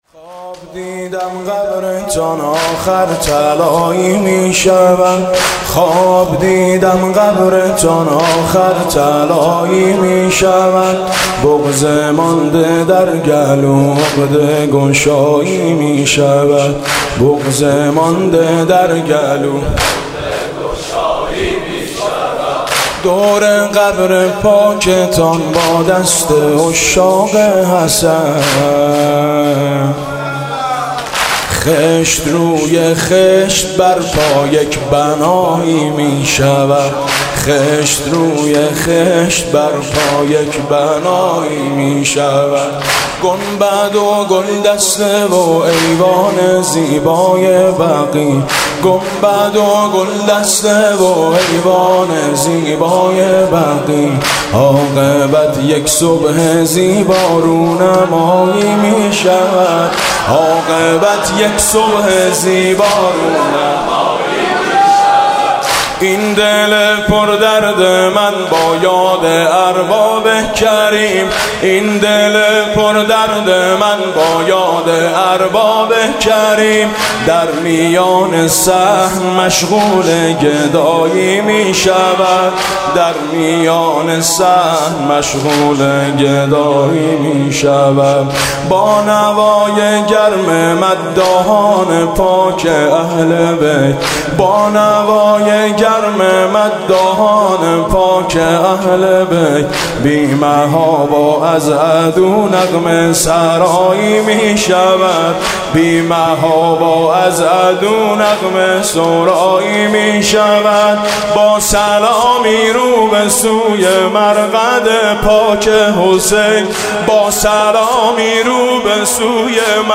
«ویژه مناسبت تخریب بقیع» زمینه: خواب دیدم قبرتان آخر طلایی می شود